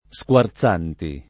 [ S k U ar Z# nti ]